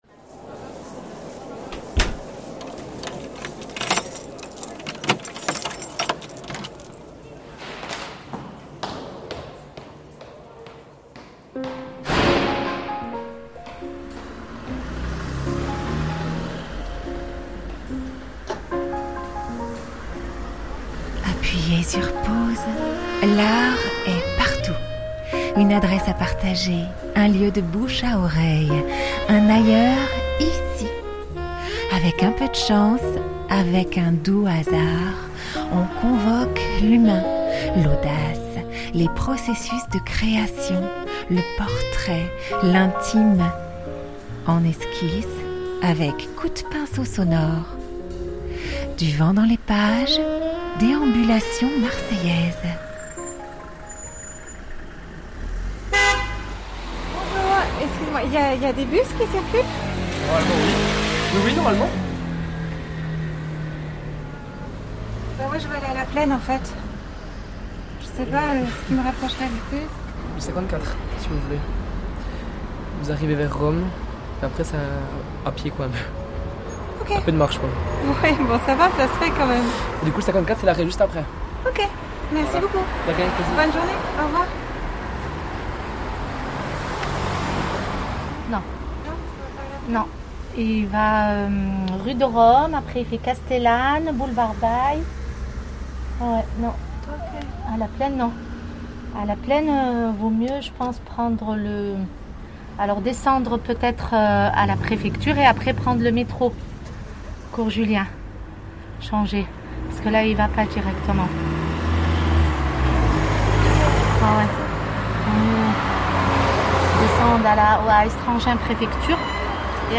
Du Vent dans les Pages Magazine sonore mensuel de l’Art autour de Marseille Littérature, Photographie, Théâtre, Arts de la rue, Arts du Cirque, Danse Contemporaine, … , du vent dans les pages vous entraîne à la rencontre des acteurs de la culture d'aujourd'hui.